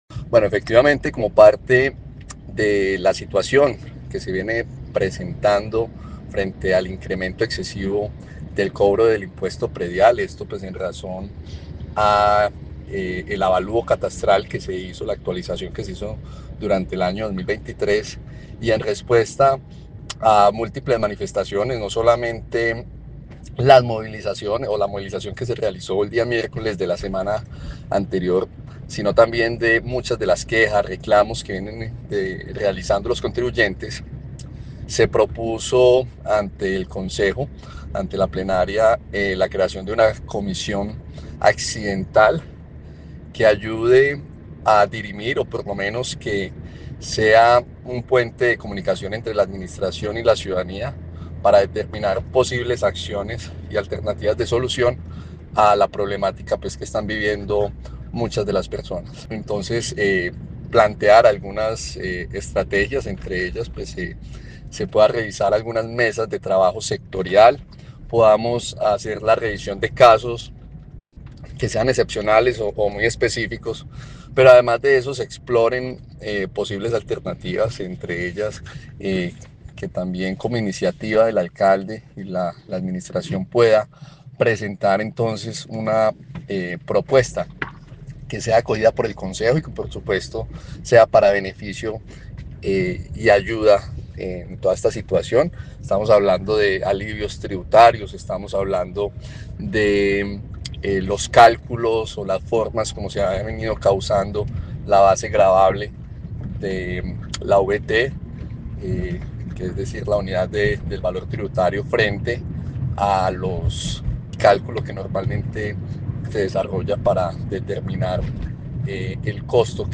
Concejal Jonatan Rojo